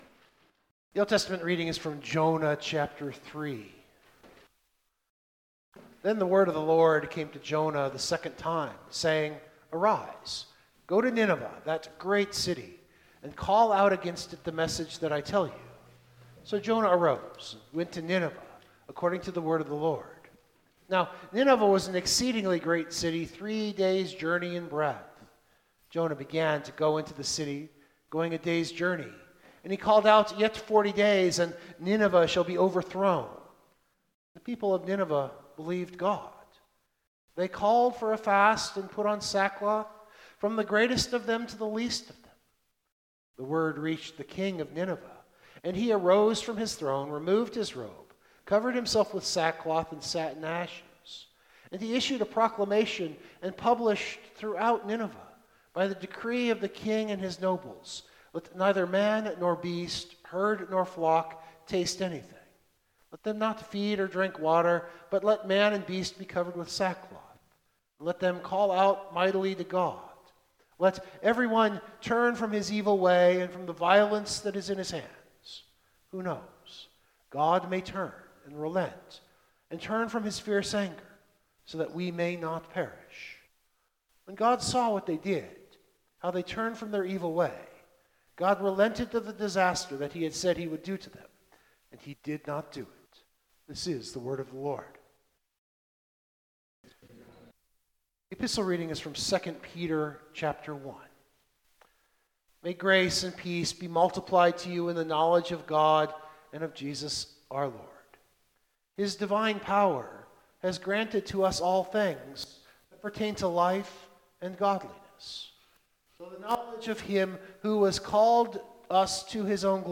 AshWed2022MBSermon.mp3